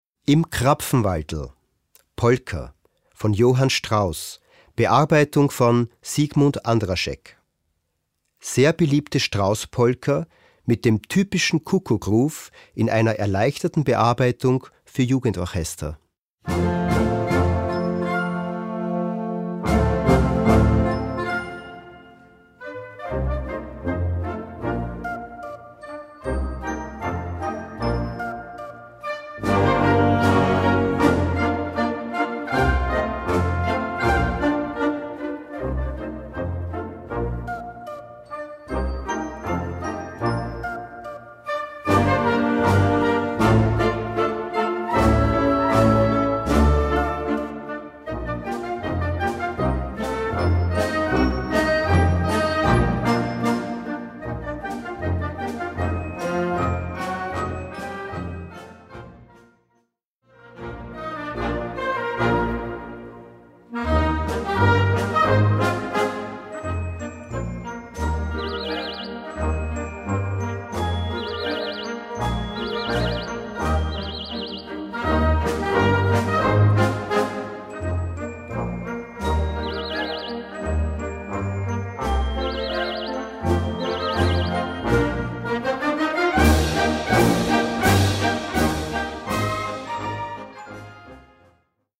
Gattung: Polka für Jugendblasorchester
Besetzung: Blasorchester